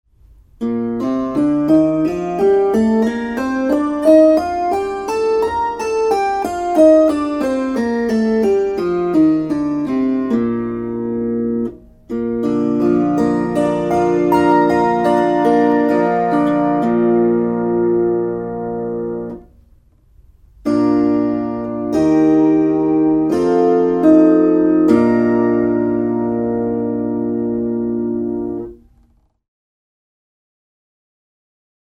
Kuuntele B-duuri. b es Opettele duurit C G D A E F Es As mollit a e h fis cis d g c f Tästä pääset harjoittelun etusivulle.